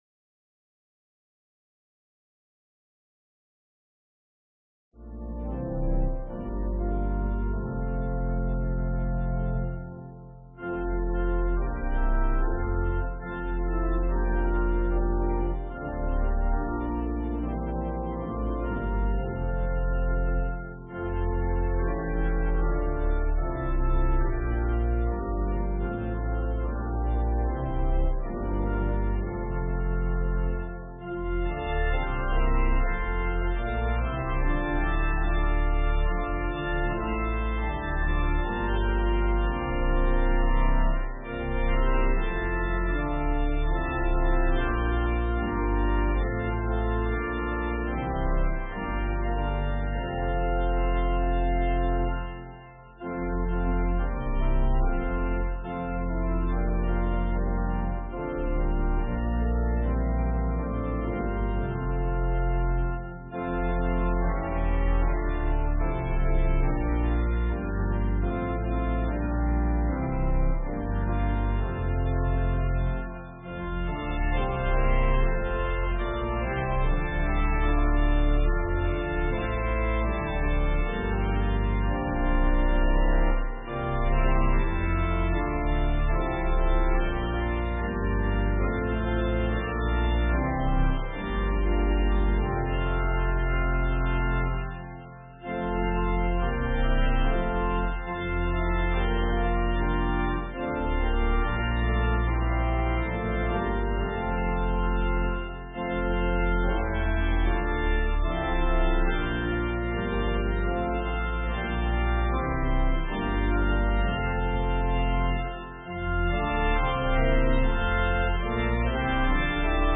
Sermon:WWJD?
Note: The prelude begins 15 minutes into the video and the audio file